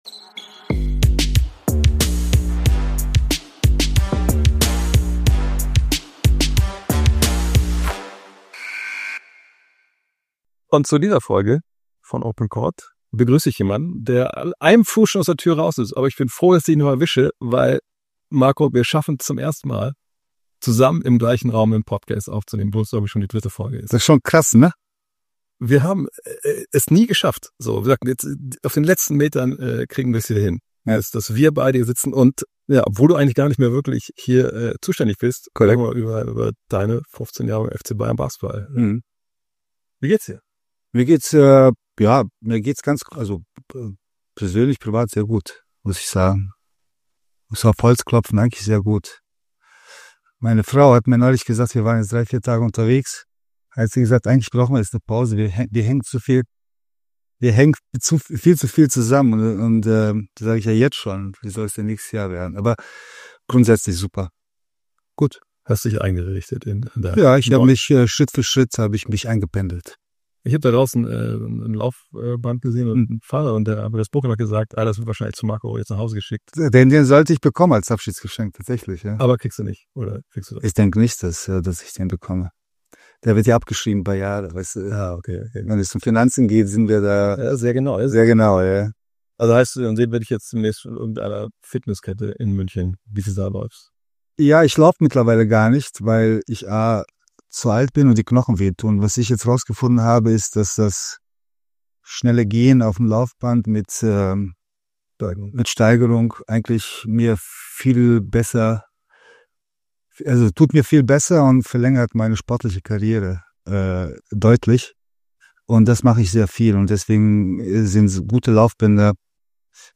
Nur der Zutritt zur FCBB-Kabine ist weder dem Traumschiffkapitän Florian Silbereisen, DJ Ötzi und nicht mal Roland Kaiser gestattet – also ist Marko Pesic hier eingetroffen zur allerletzten Amtshandlung als Geschäftsführer der Bayern, die er zum 1. Januar nun tatsächlich verlässt nach knapp 15 Jahren, zumindest in verantwortlicher Position: für ein ausführliches Gespräch bei OPEN COURT, dem Podcast seines Vereins, dessen Baumeister, Fieberthermometer, Impulsgeber, Halsschlagader, Stratege, Antreiber, Sprachrohr und Stratege er so lange war.